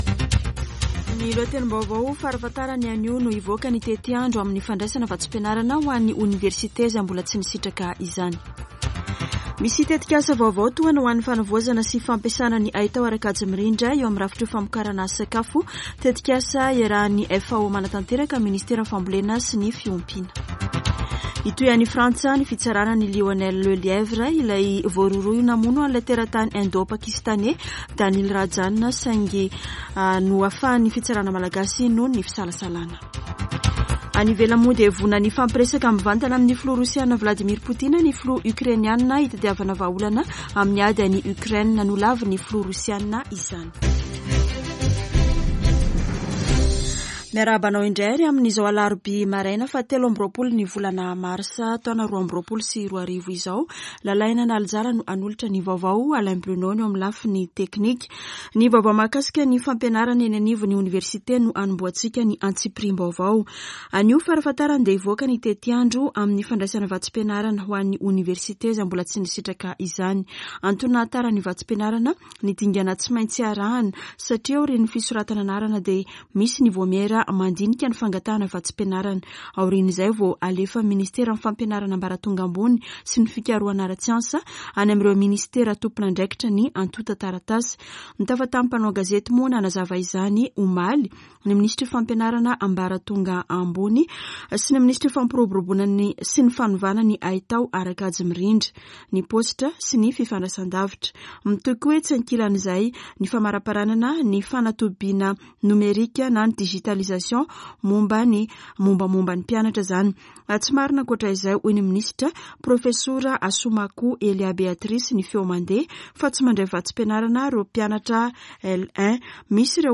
[Vaovao maraina] Alarobia 23 marsa 2022